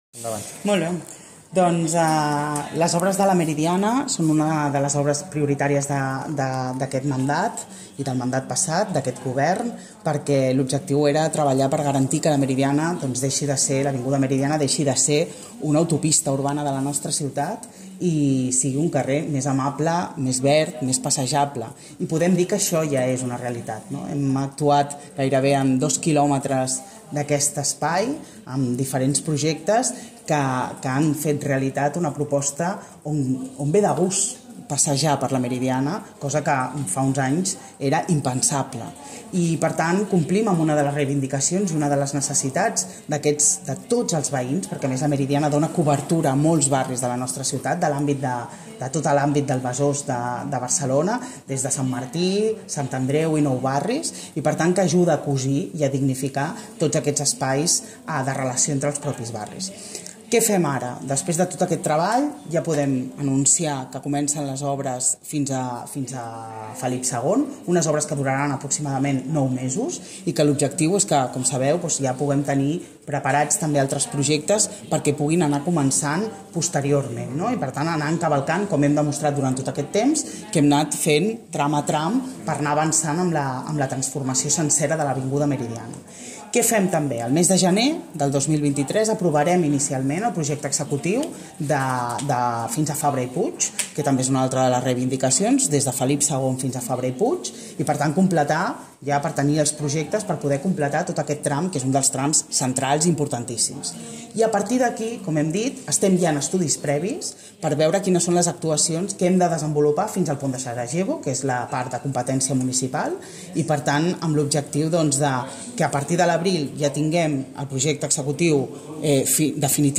Declaracions de Janet Sanz (MP3):